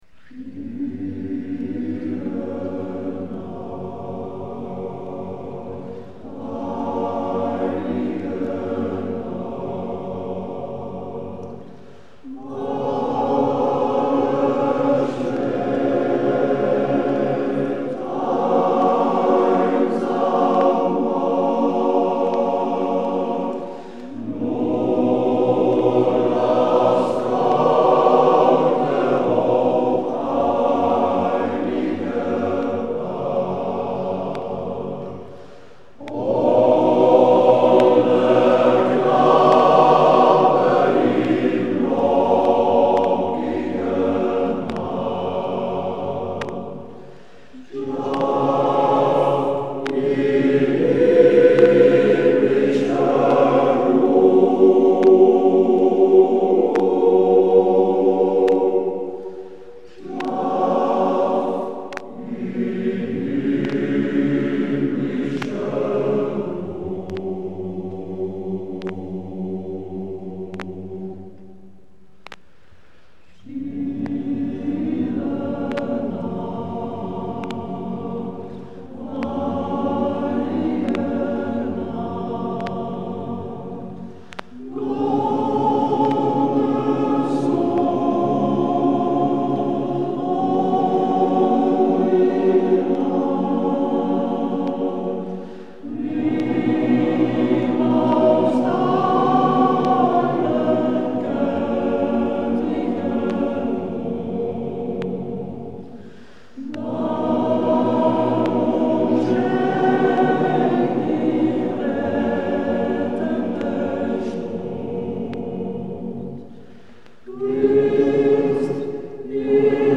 Geleens Mannenkoor Mignon